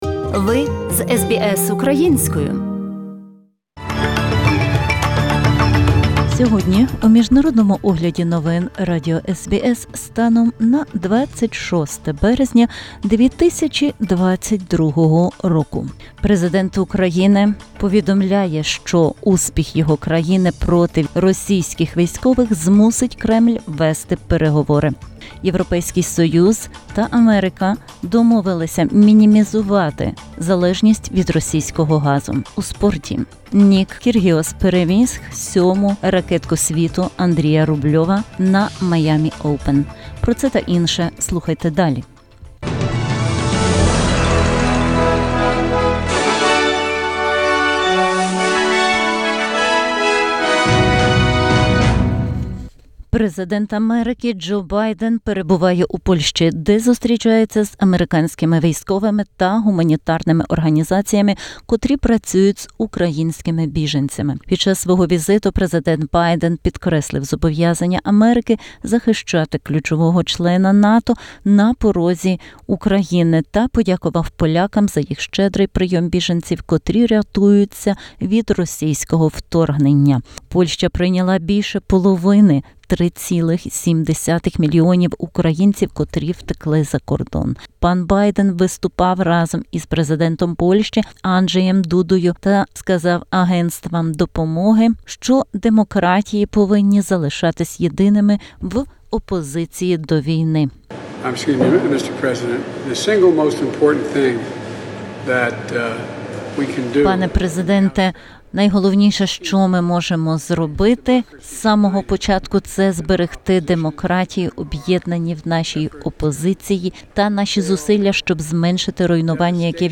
SBS новини українською - 26/03/2022